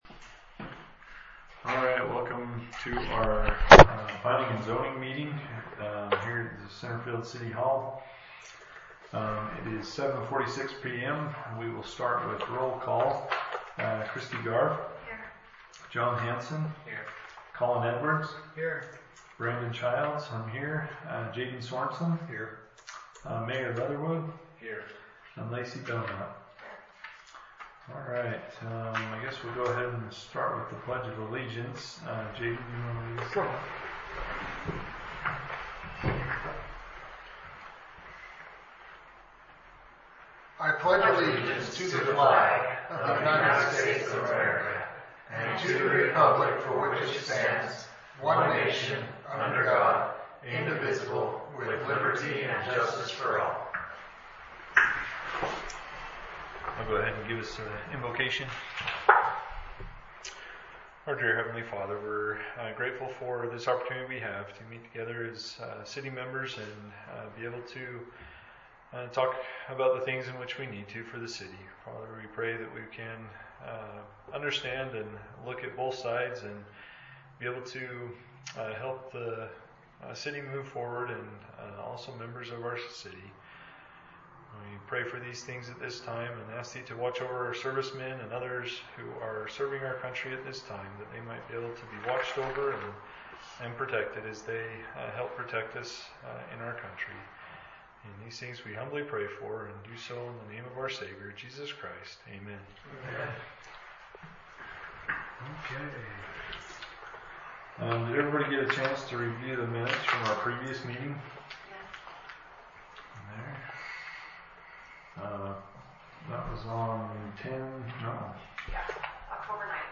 Centerfield Planning Commission Planning & Zoning Meeting
Notice is hereby given that the Planning Commission will hold a commission meeting on October 30, 2025 at the Centerfield City Hall, 130 South Main; the meeting will begin promptly at 7:30 p.m.